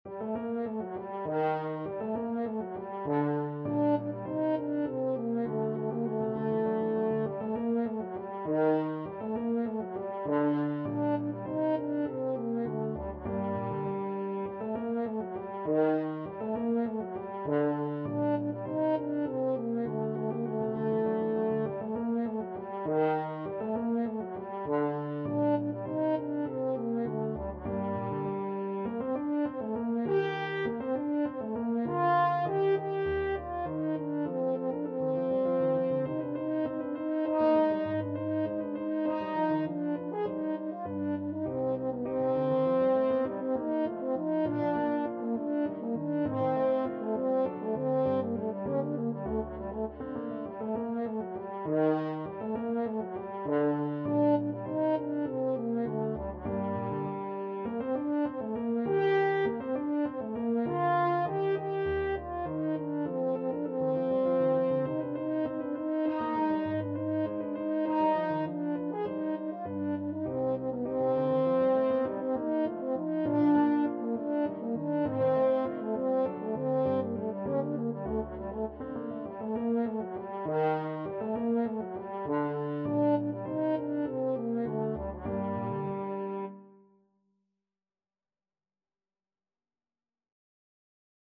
3/4 (View more 3/4 Music)
Allegretto = 100
Classical (View more Classical French Horn Music)